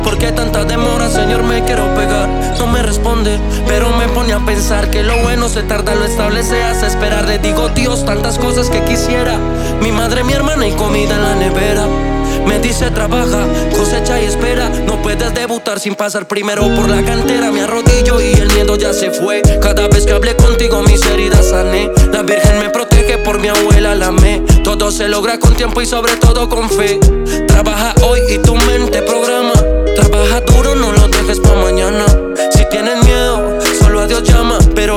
Жанр: Латино
Latin, Urbano latino